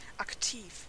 Ääntäminen
IPA: [akˈtiːf]